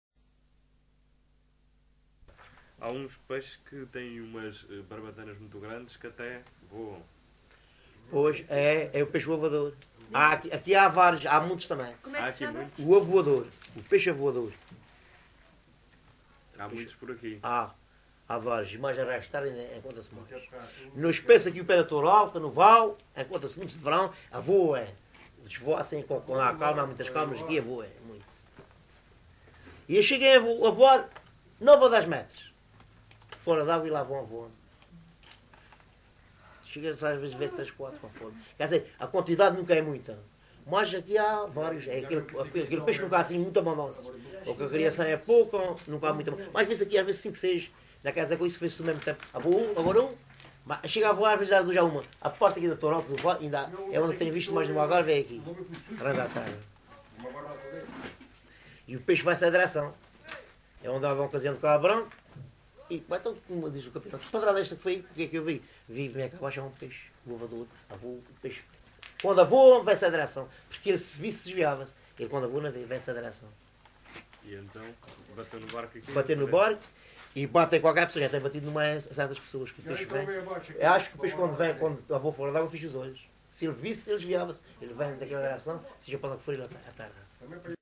LocalidadeAlvor (Portimão, Faro)